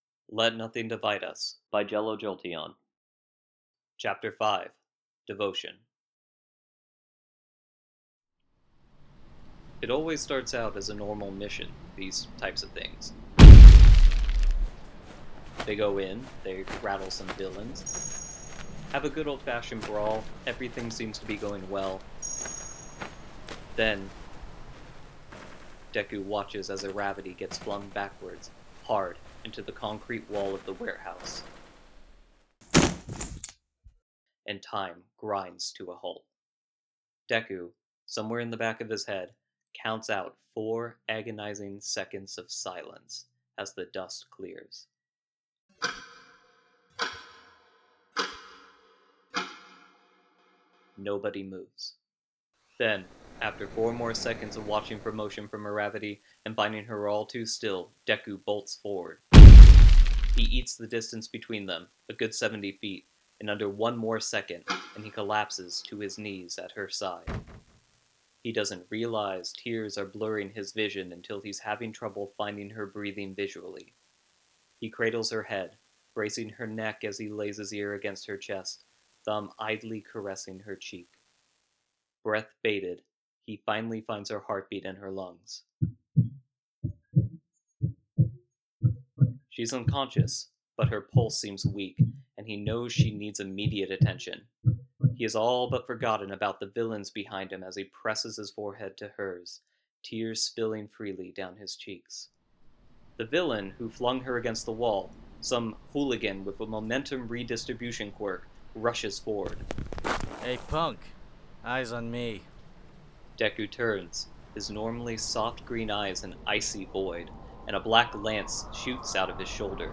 Let Nothing Divide Us : Chapter 5 - Devotion | Podfic